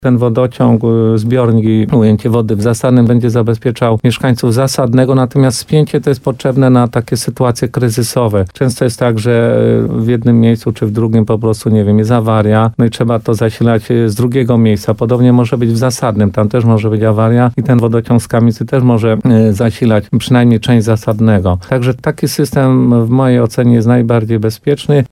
Wójt Władysław Sadowski mówił w programie Słowo za słowo na antenie RDN Nowy Sącz, że część mieszkańców Zasadnego ma wątpliwość, co do tego pomysłu.